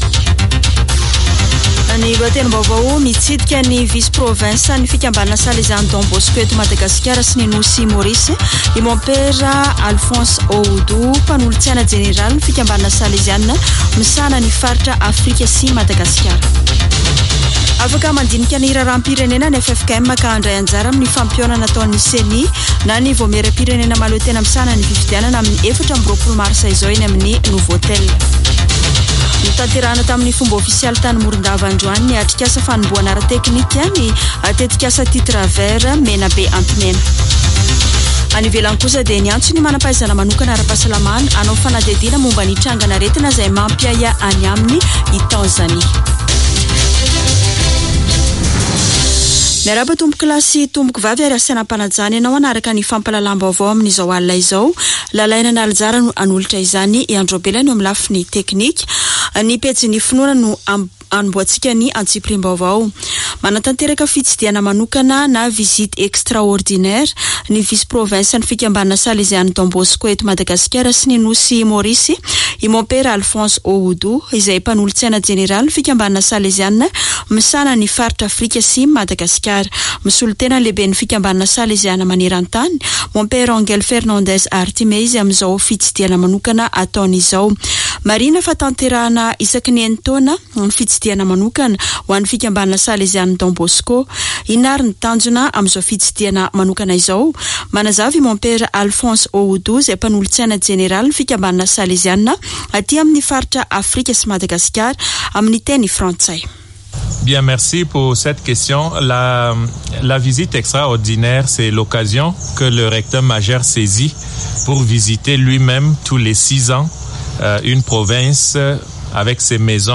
[Vaovao hariva] Zoma 17 marsa 2023